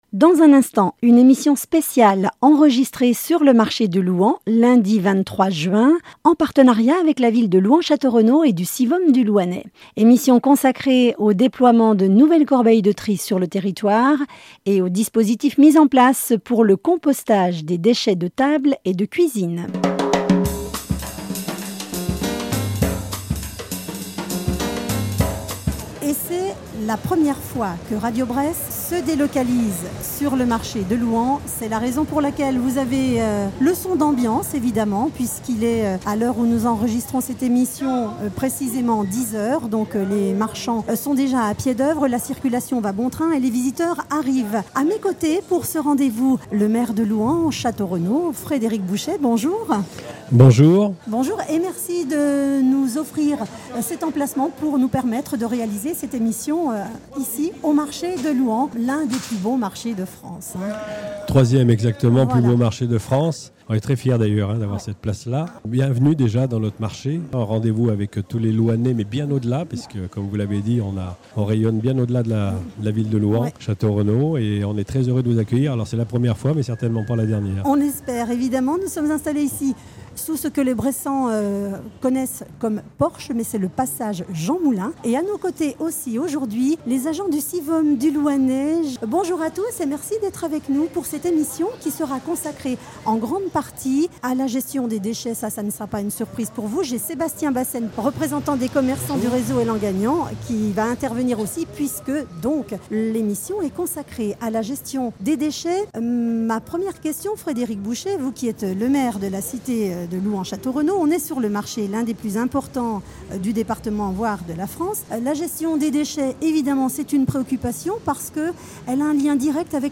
🎧Écoutez l’émission enregistrée au marché de Louhans ! 📍 Enregistrée lundi matin sur le marché de Louhans (SIVOM) 📅 Diffusée le mercredi 25/06/2025 à 10h sur Radiobresse